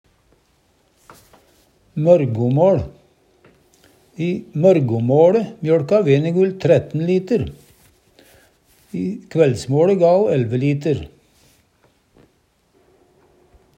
mørgomåL - Numedalsmål (en-US)